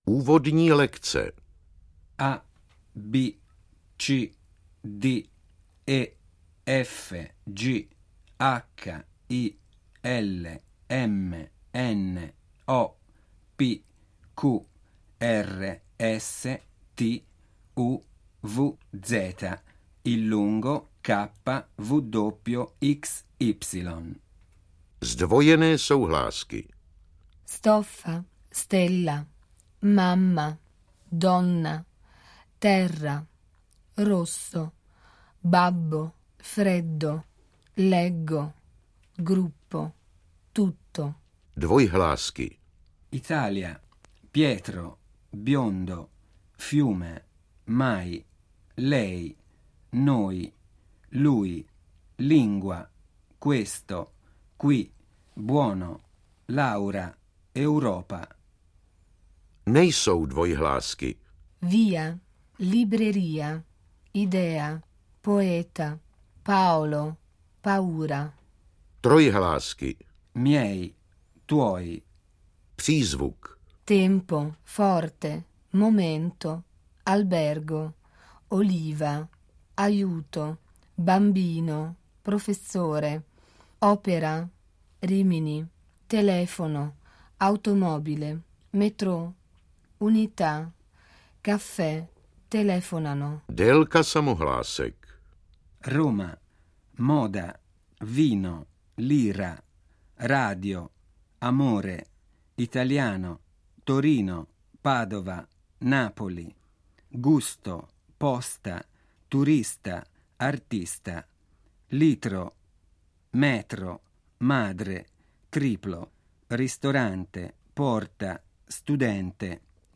Vše jen namluveno italsky bez českeho překladu sloviček.
AudioKniha ke stažení, 4 x mp3, délka 1 hod. 53 min., velikost 102,9 MB, česky